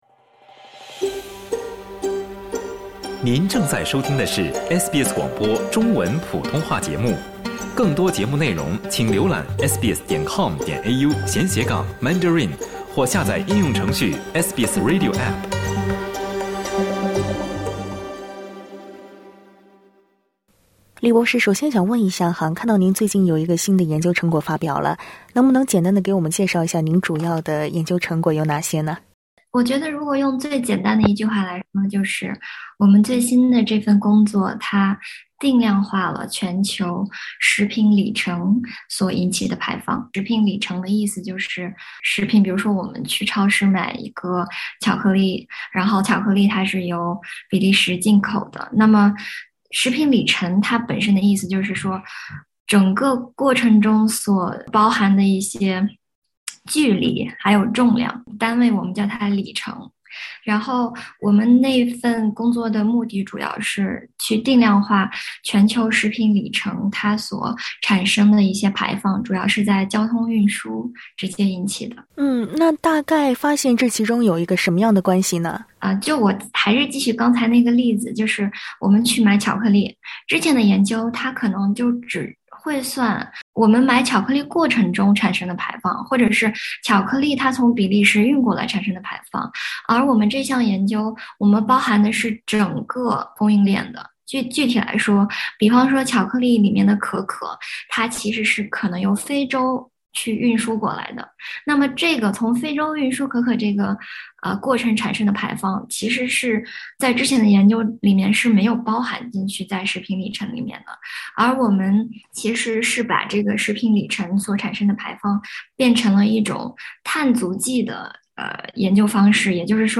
气候问题与每个人都息息相关，但身为消费者，我们每天购买的食物，都能对碳排放产生重大影响。（点击上图收听采访）